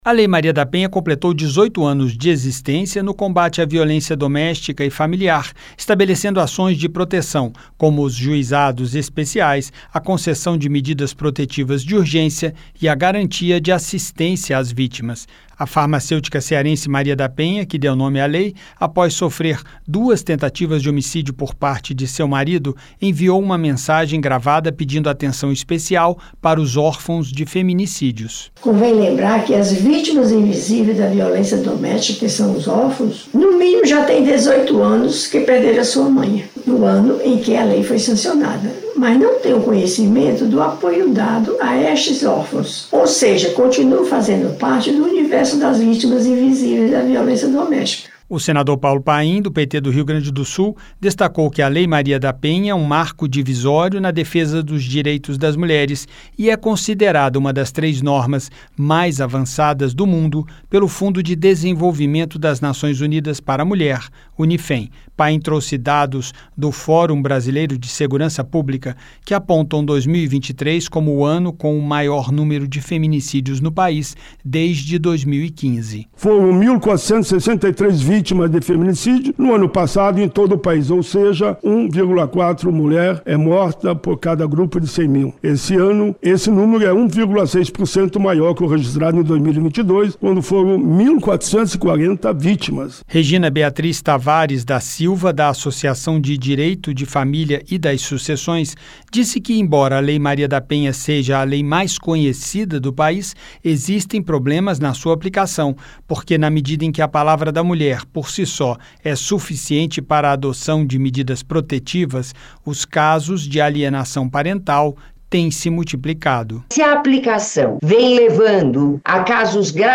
A Comissão de Direitos Humanos (CDH) promoveu uma audiência pública nesta segunda-feira (16) para discutir aprimoramentos na aplicação da Lei Maria da Penha, que completou 18 anos de existência. O senador Paulo Paim (PT-RS), que conduziu a reunião, citou dados do Fórum de Segurança Pública que apontam 2023 como o ano com o maior número de feminicídios no país desde 2015: foram registradas 1.463 mortes.